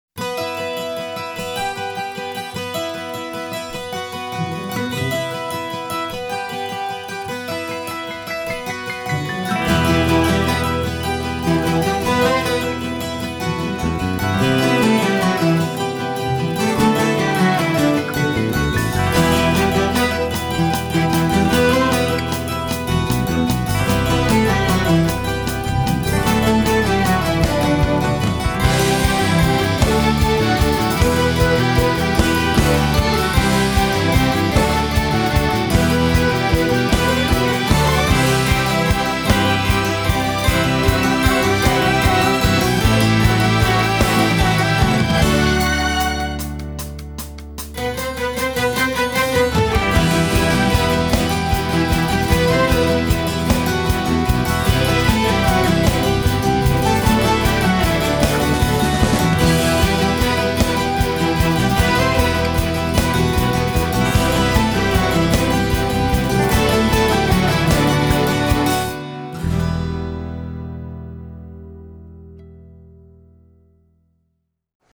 Танцевальные
инструментальные